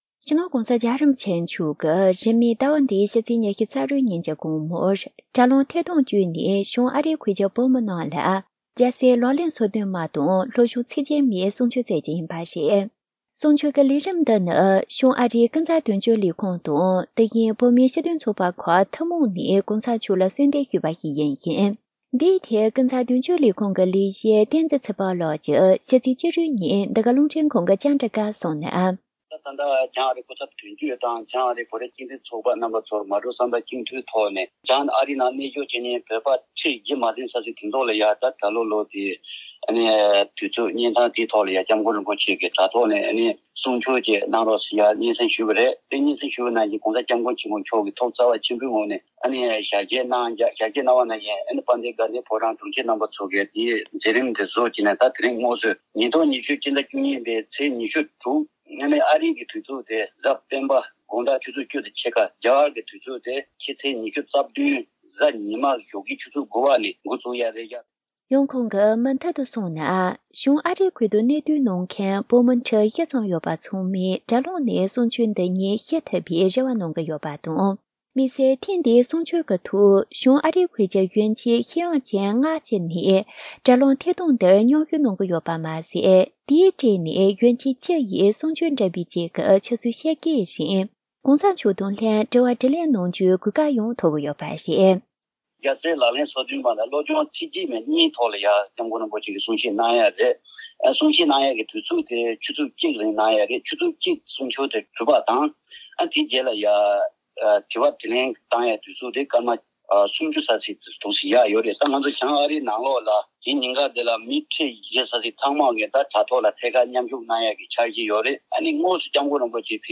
འབྲེལ་ཡོད་ལ་བཅར་འདྲི་ཞུས་ནས་གནས་ཚུལ་ཕྱོགས་བསྒྲིགས་ཞུས་པར་གསན་རོགས།།